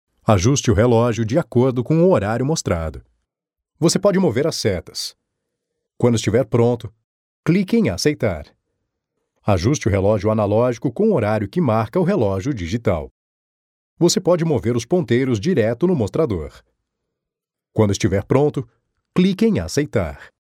locutor Brasil.
Brazilian voice talent